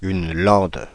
Ääntäminen
Ääntäminen Paris Tuntematon aksentti: IPA: /lɑ̃d/ Haettu sana löytyi näillä lähdekielillä: ranska Käännöksiä ei löytynyt valitulle kohdekielelle.